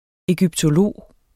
Udtale [ εgybtoˈloˀ ]